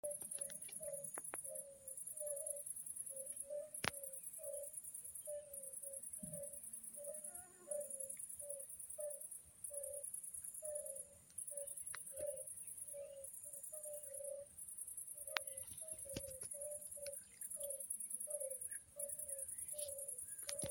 Data resource Xeno-canto - Anura sounds from around the world